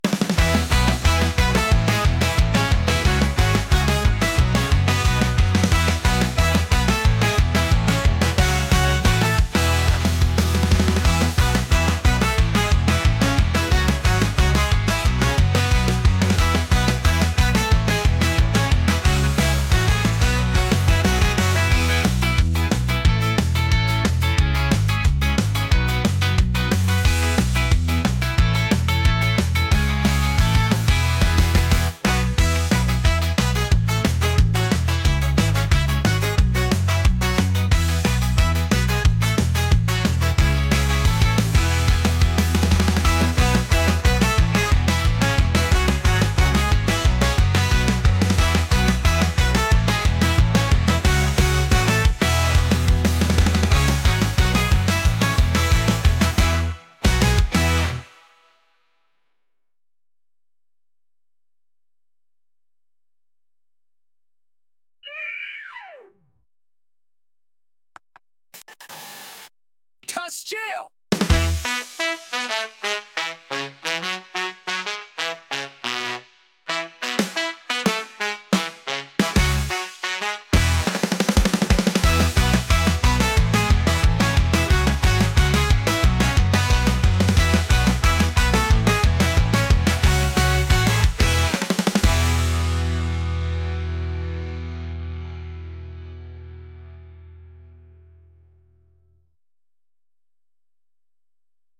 energetic | ska | punk